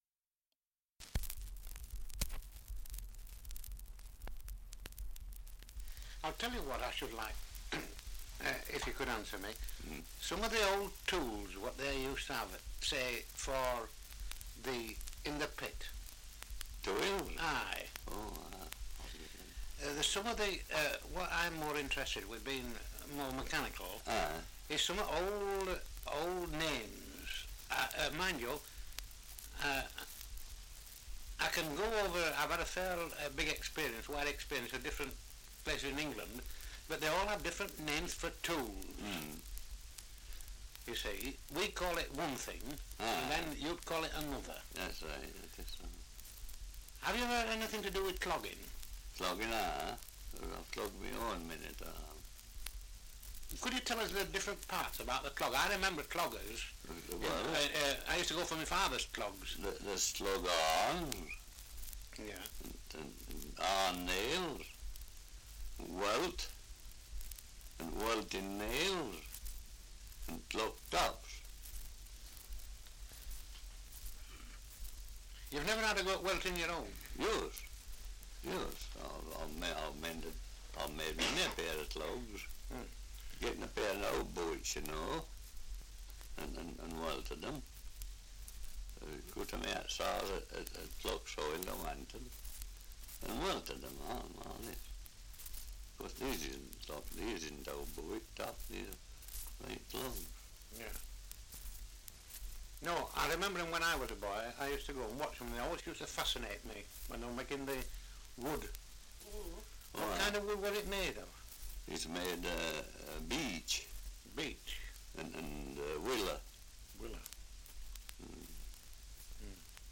Survey of English Dialects recording in Skelmanthorpe, Yorkshire
78 r.p.m., cellulose nitrate on aluminium